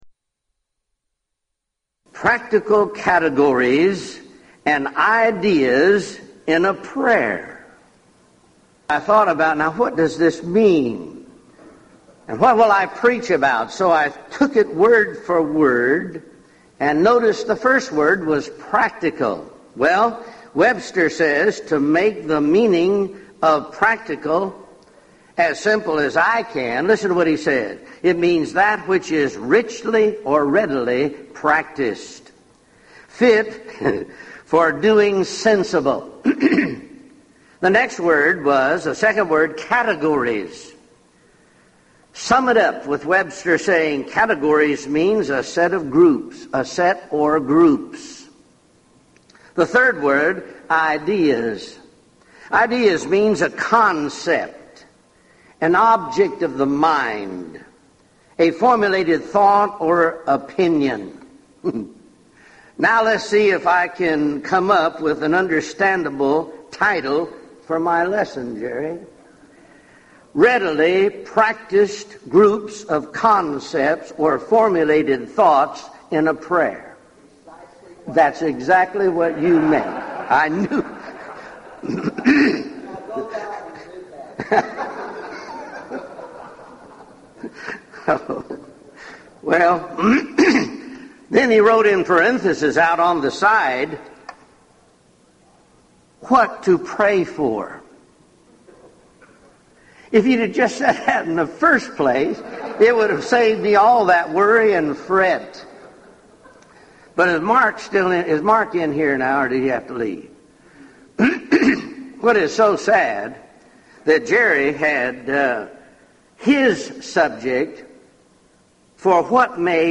Event: 1998 Gulf Coast Lectures